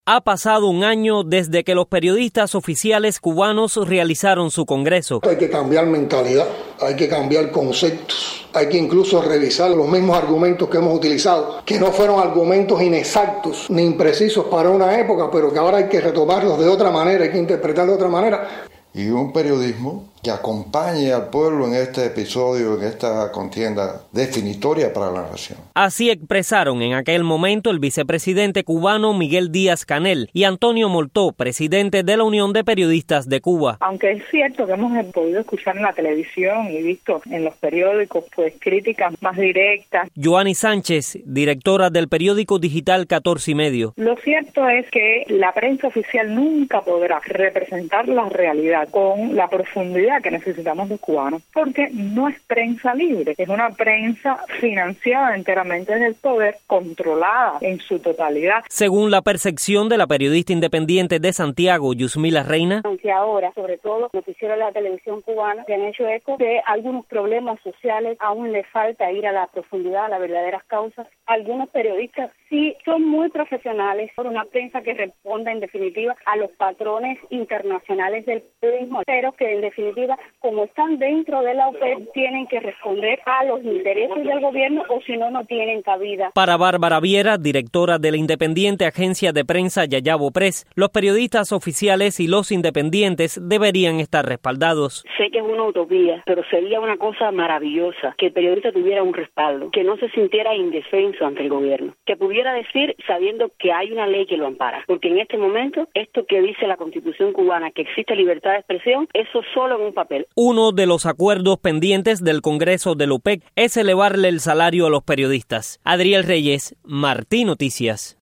Luego de transcurrido un año del Congreso de la UPEC, tres periodistas independientes opinan sobre la realidad de este sector en la isla.